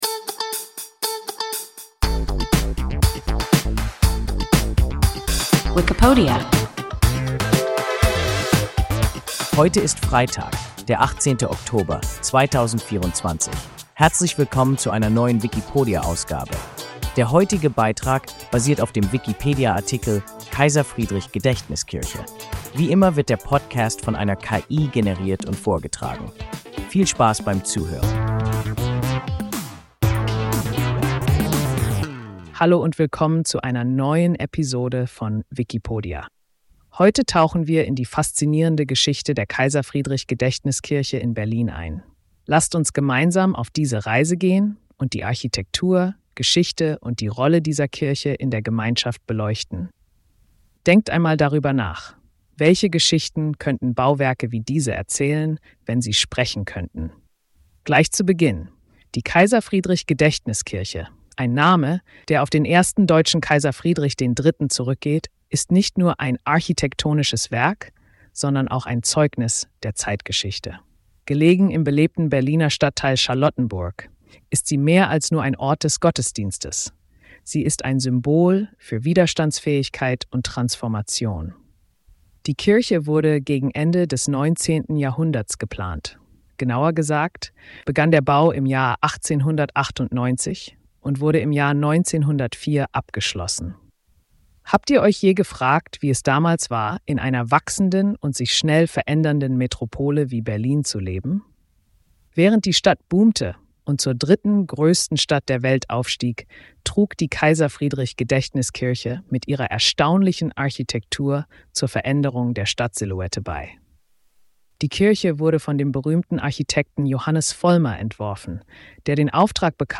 Kaiser-Friedrich-Gedächtniskirche – WIKIPODIA – ein KI Podcast